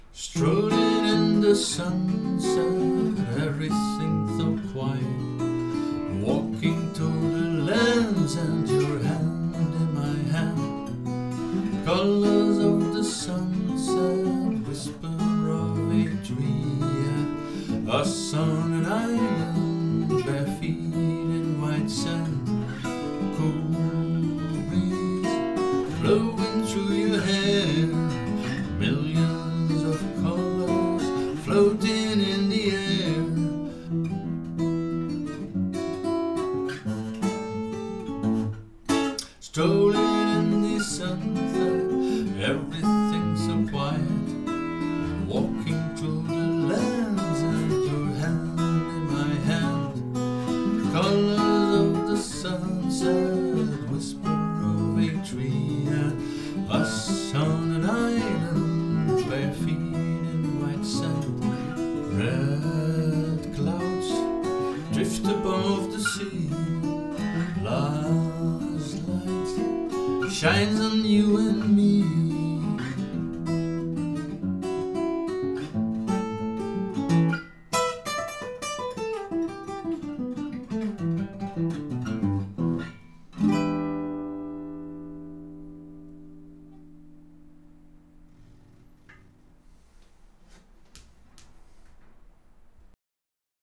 Since then they perform as a trio.